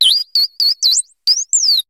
Cri de Shaymin dans Pokémon HOME.